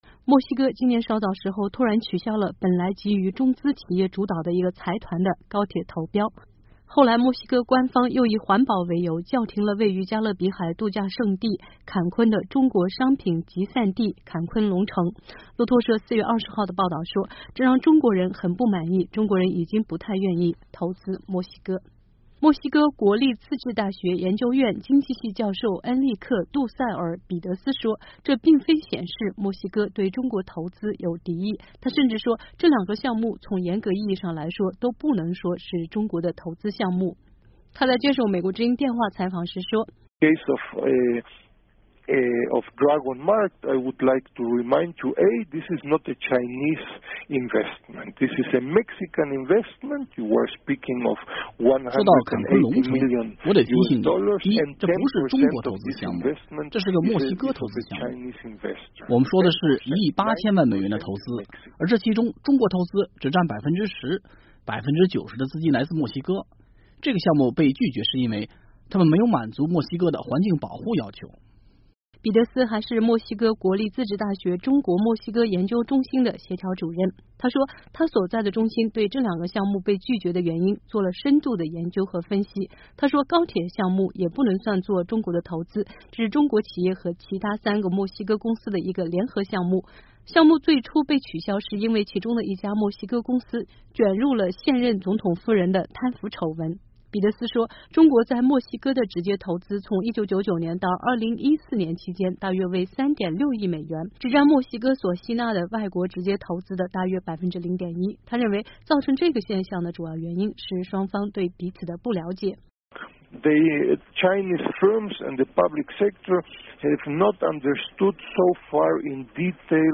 他在接受美国之音电话采访时说： “说到‘坎昆龙城’，我得提醒你，第一，这不是中国投资项目，这是个墨西哥投资项目。我们说的是1.8亿美元的投资，而这其中中国投资只占10%， 90%的资金来自墨西哥。这个项目被拒绝是因为他们没有满足墨西哥的环境保护要求。”